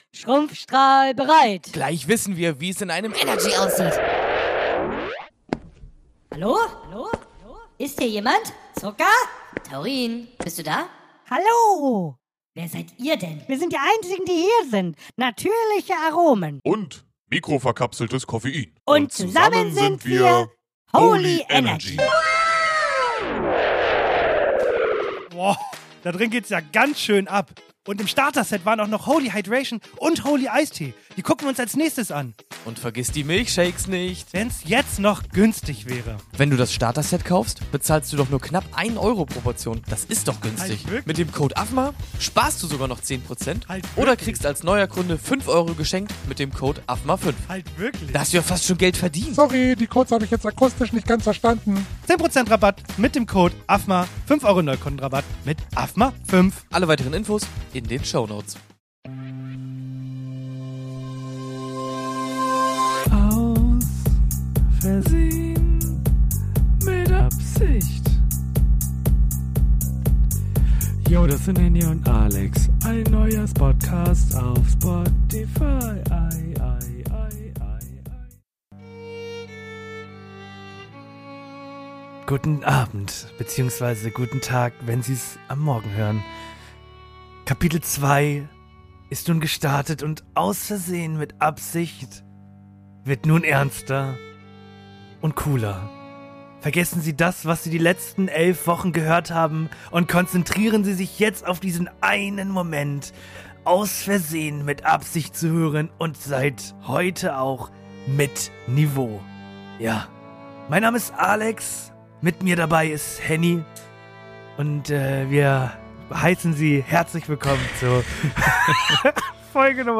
Achso, zwischendurch machen wir so Delfin- und Fledermausgeräusche nach, komplett nice.